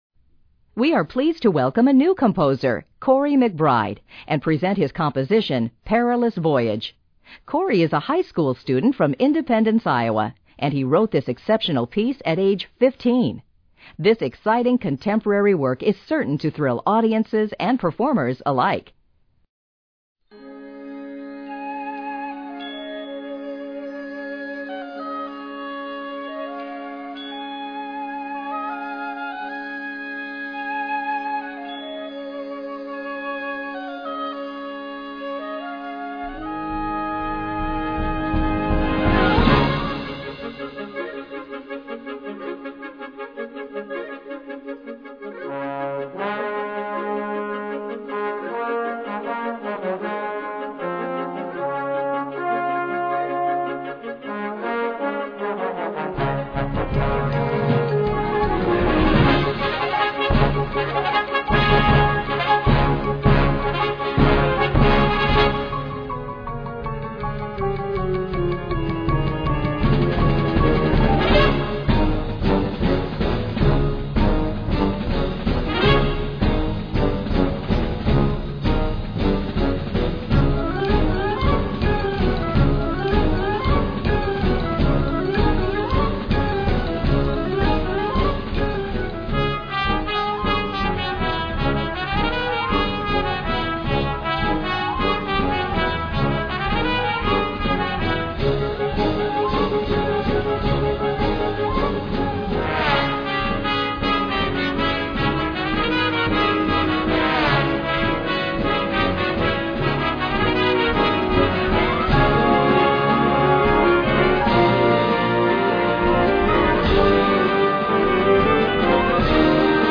Categorie Harmonie/Fanfare/Brass-orkest
Subcategorie Concertmuziek
Bezetting Ha (harmonieorkest)
korte solo-statements voor zowel fluit als hobo